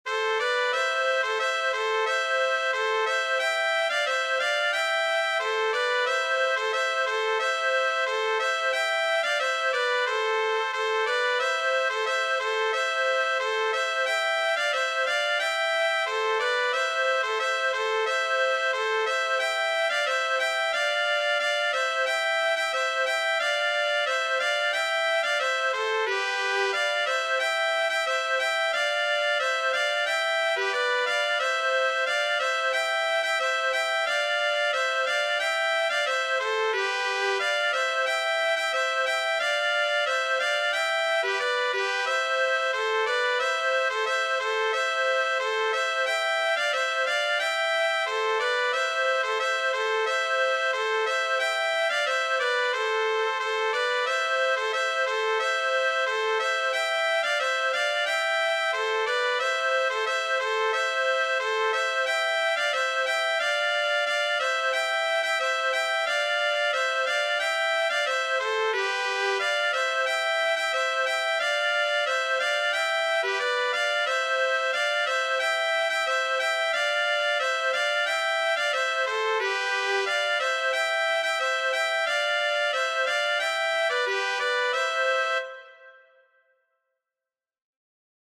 风格 an dro
乐器 笛子单簧管小号
调性 C大调
节拍 4/4
速度 ♩=90